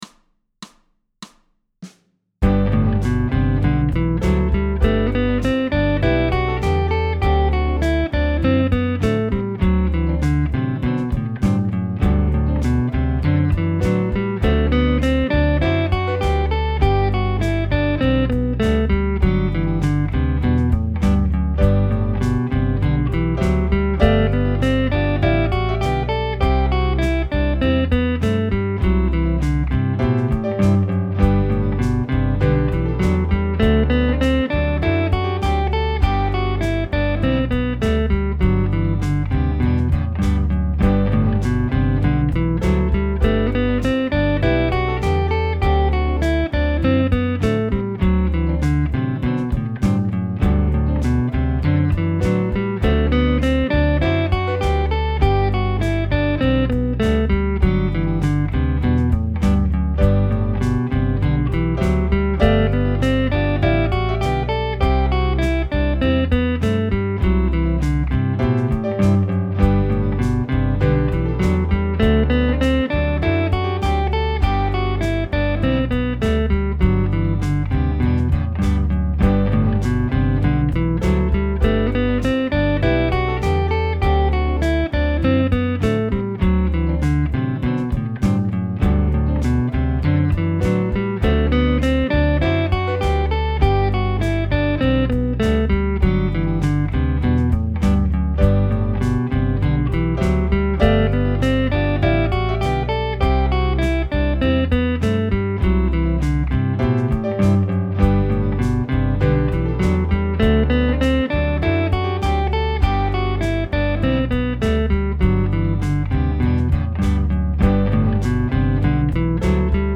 major-scale-with-guitar-g.mp3